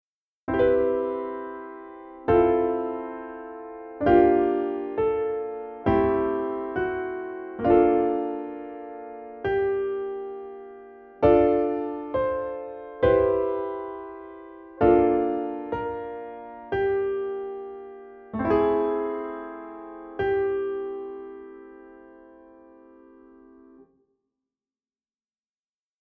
To stir some musical creativity here is an example of some basic chords I worked out and then expanded into something more.
Finally I kept the chords in closed position for simplicity’s sake.